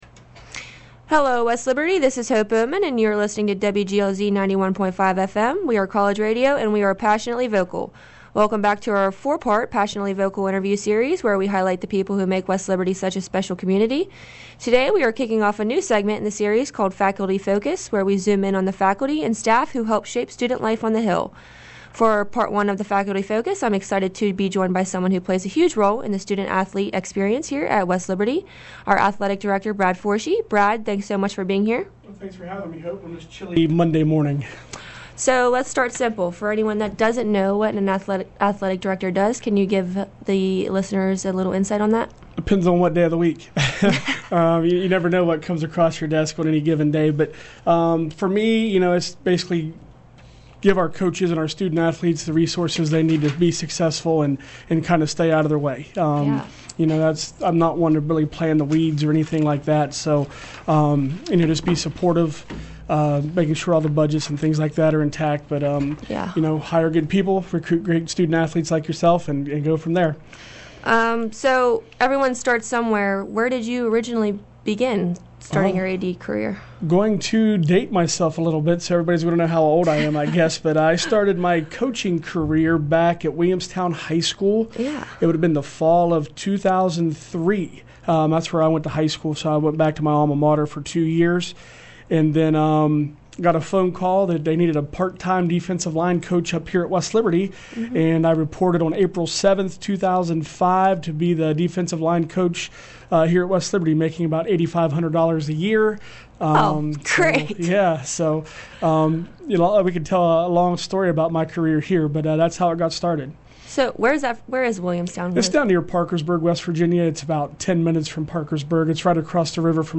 “Faculty Focus” Interview